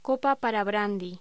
Locución: Copa para brandy
voz